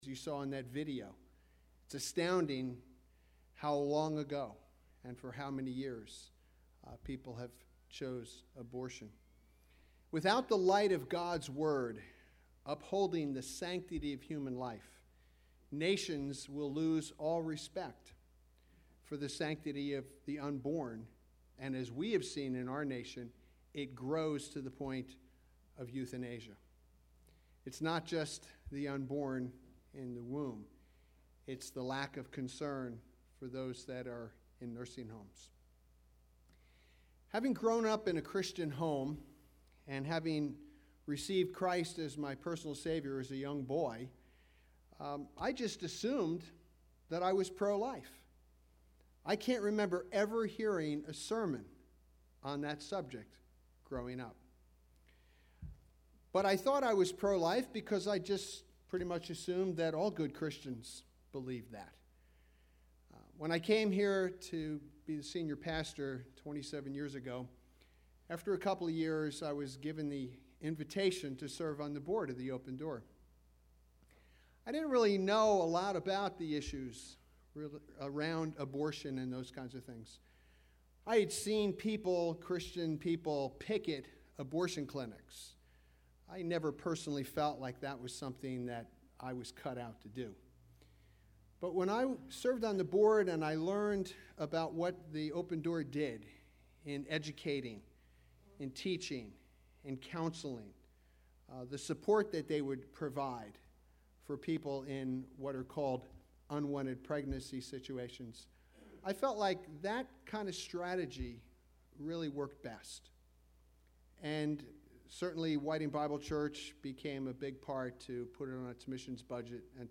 Jeremiah 1:5 Service Type: Sunday AM Service Topics: Sanctity of Human Life « I Do Not Condemn You!